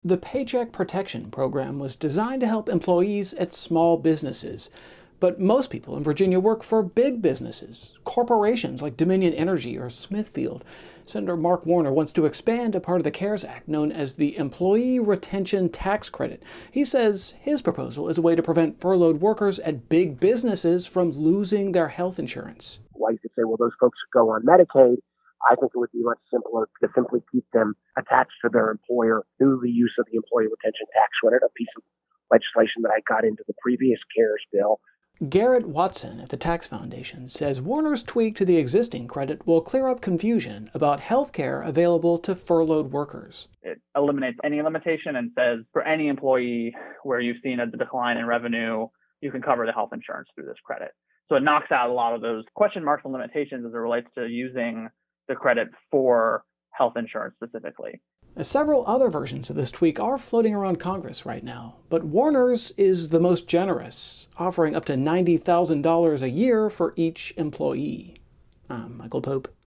This report, provided by Virginia Public Radio, was made possible with support from the Virginia Education Association.